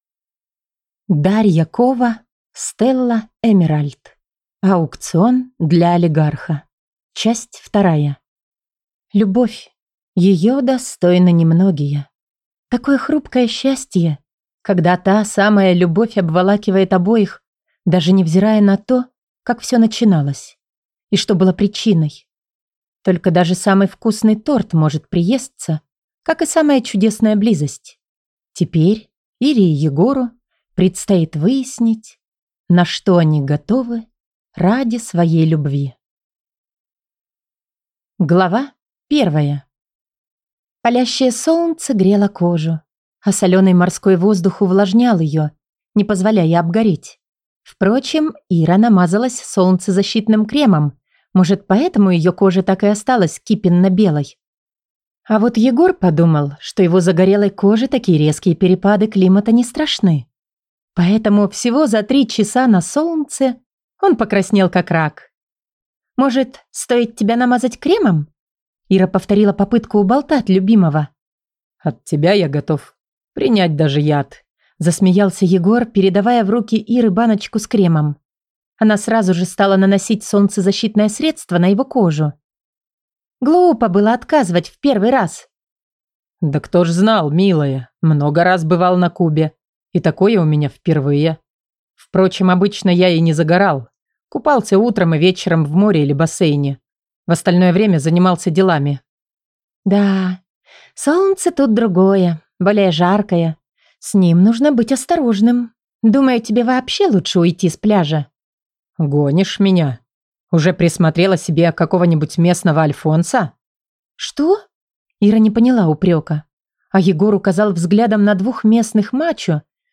Аудиокнига Аукцион для олигарха 2 | Библиотека аудиокниг